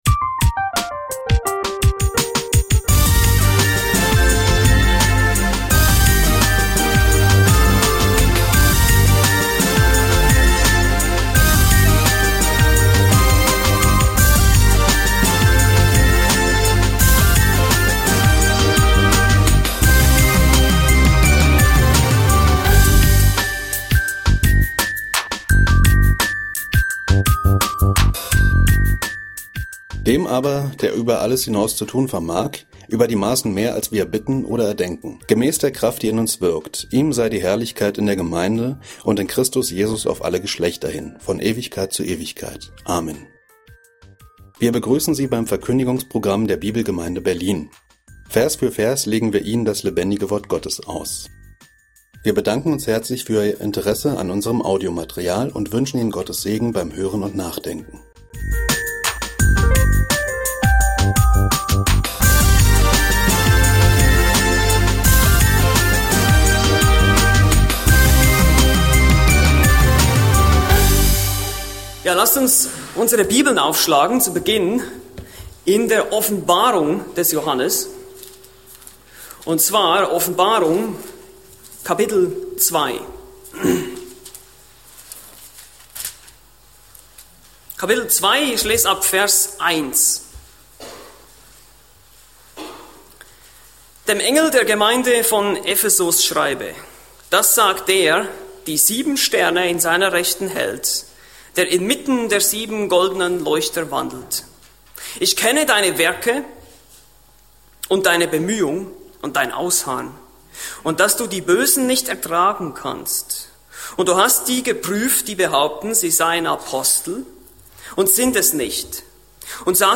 Predigt: "Anthropologie (3)"